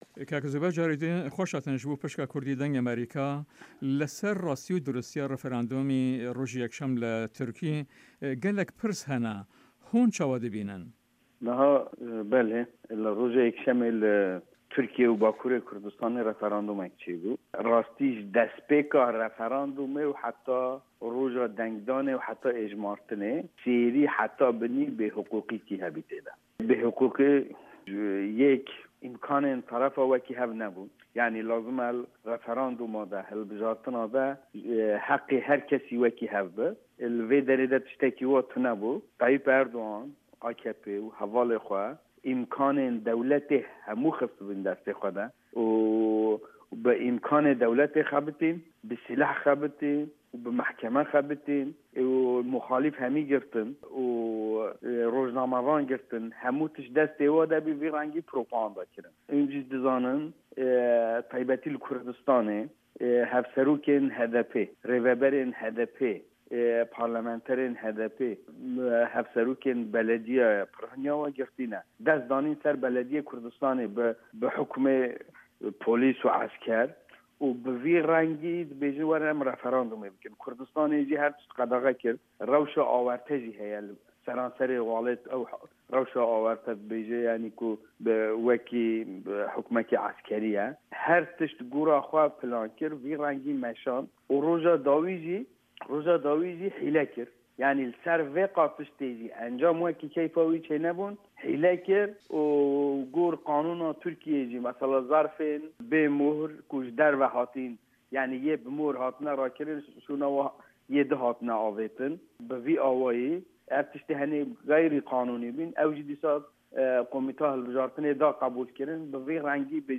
Interview with Zübeyir Aydar